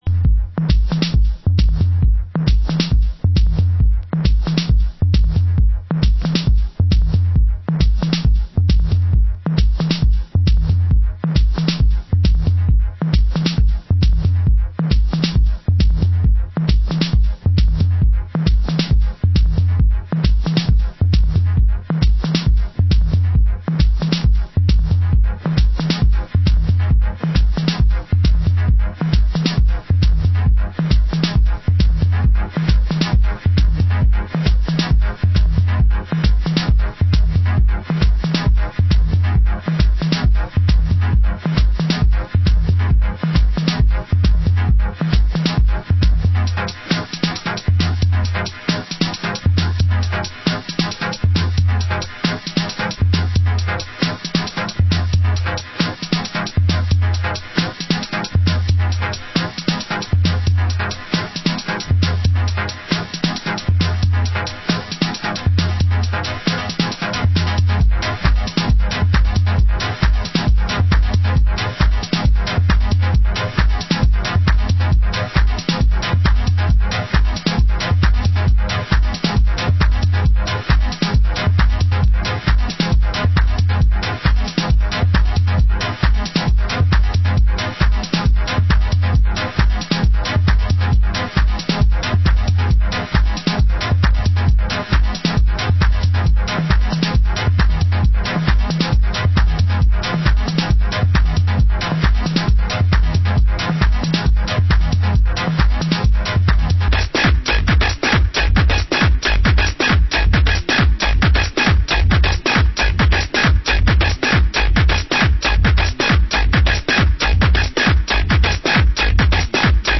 Genre: UK Techno